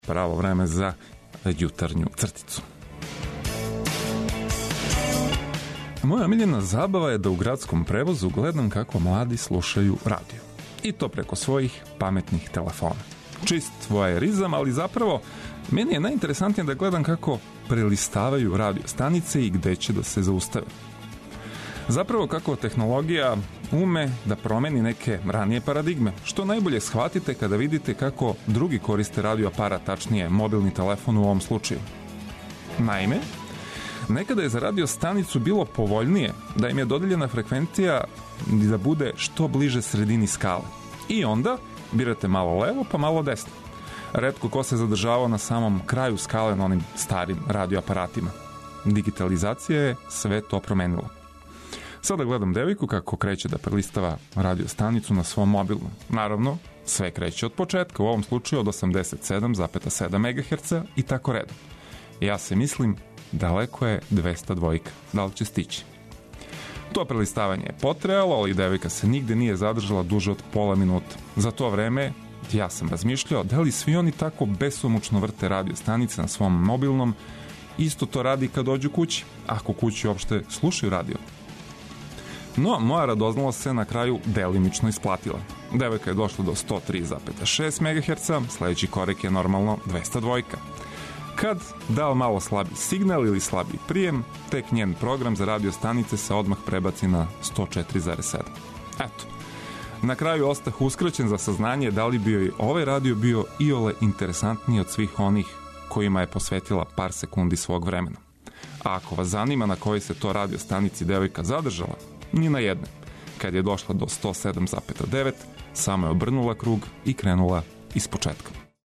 И за једне и за друге овог јутра ћемо вам понудити добру музику и неке интересантне приче, као и најнужније информације!